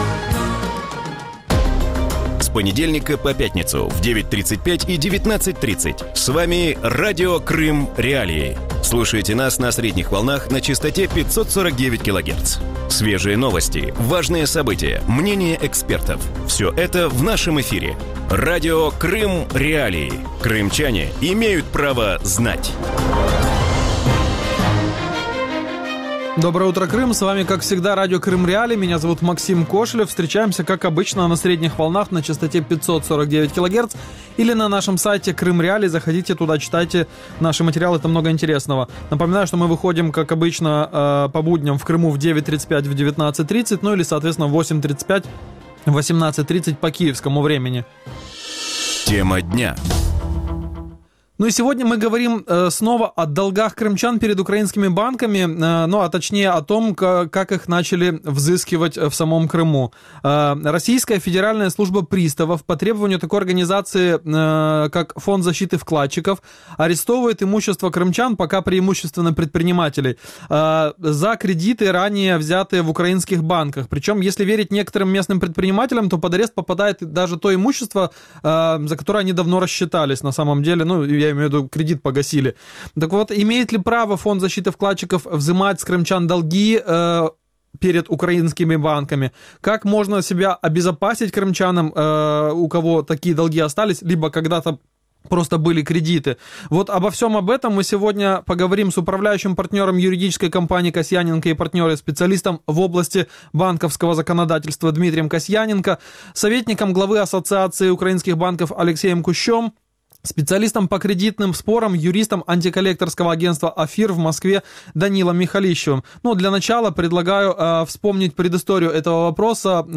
В утреннем эфире Радио Крым.Реалии говорят о долгах крымчан перед украинскими банками.